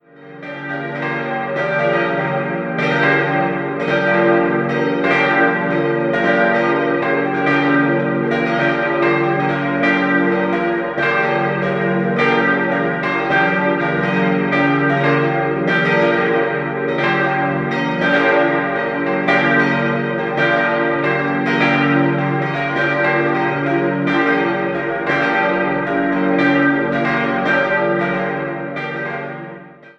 Die Einweihung fand 1881 statt. Idealquartett d'-f'-g'-b' Die kleine Glocke stammt noch aus der Zwischenkriegszeit von Johann Hahn (Landshut/Reichenhall). Die drei anderen wurden 1949/50 von Karl Czudnochowsky in Erding gegossen.